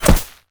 bullet_impact_grass_07.wav